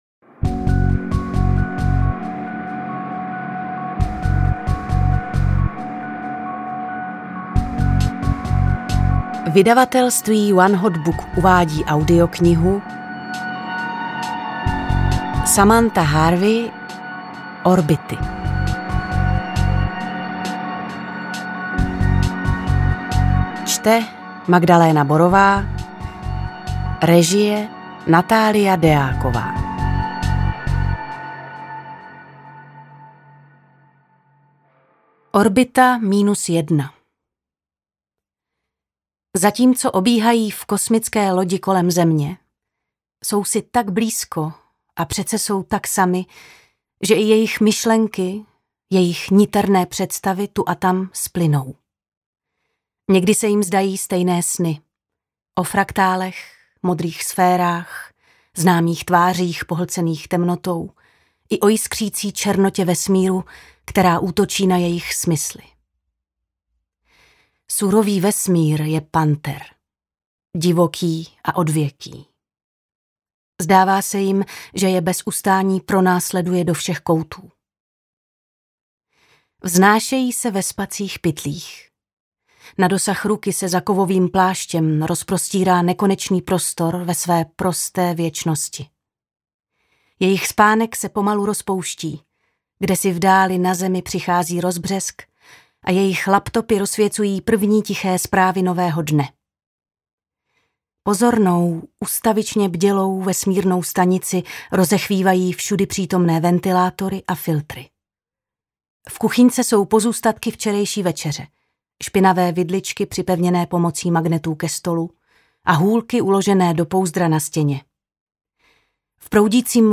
Interpret:  Magdaléna Borová
AudioKniha ke stažení, 18 x mp3, délka 6 hod. 12 min., velikost 336,0 MB, česky